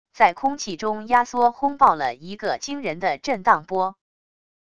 在空气中压缩轰爆了一个惊人的震荡波wav音频